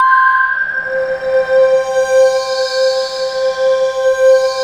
Index of /90_sSampleCDs/USB Soundscan vol.13 - Ethereal Atmosphere [AKAI] 1CD/Partition D/04-ACTUALSYN